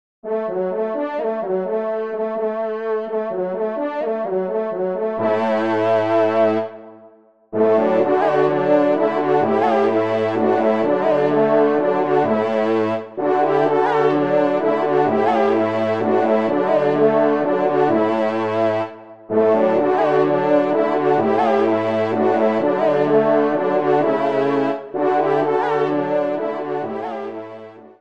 TON DE VÈNERIE   :
Pupitre Basse (en exergue)